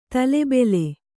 ♪ tale bele